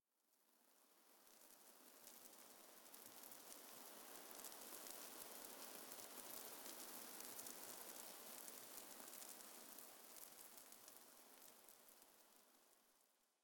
Minecraft Version Minecraft Version snapshot Latest Release | Latest Snapshot snapshot / assets / minecraft / sounds / ambient / nether / basalt_deltas / debris3.ogg Compare With Compare With Latest Release | Latest Snapshot
debris3.ogg